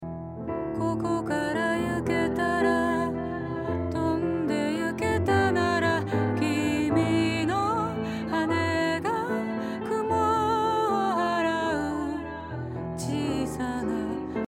ディレイのみ
ボーカルは手前に位置して少し遅れてエフェクト音がついていますね（ディレイとは遅れ…ですし）。